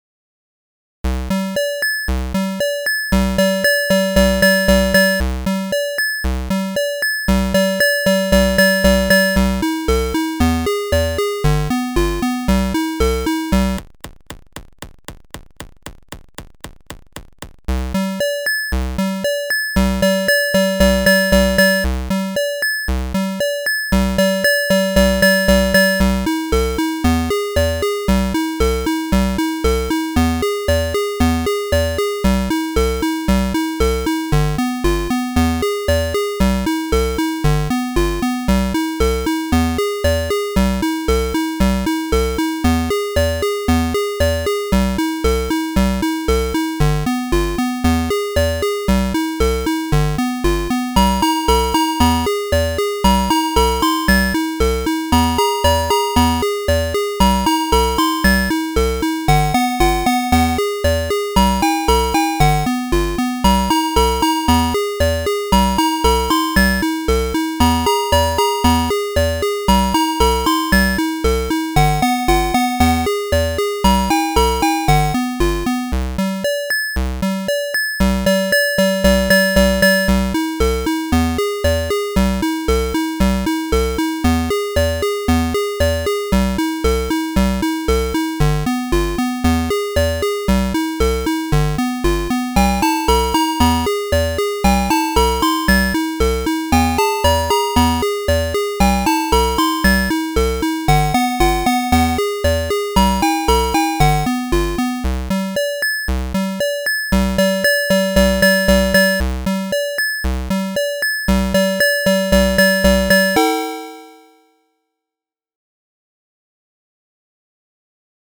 Atari-ST Emulation